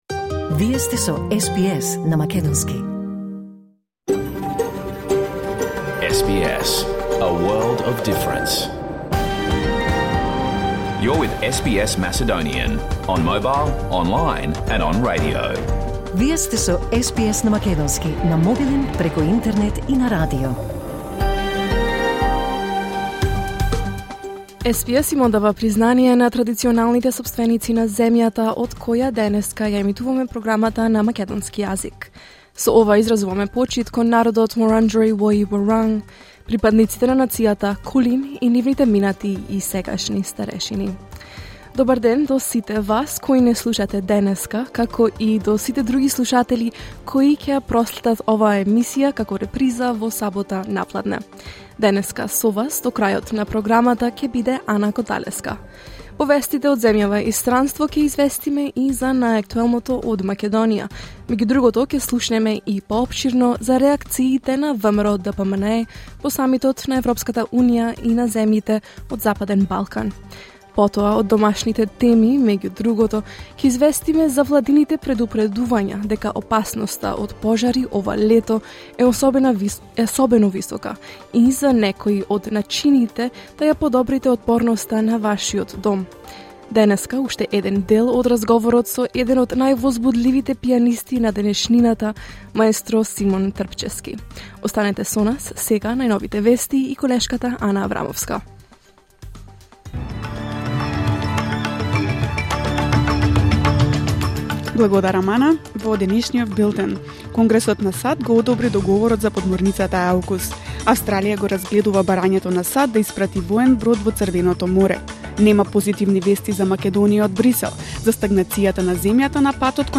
SBS Macedonian Program Live on Air 15 December 2023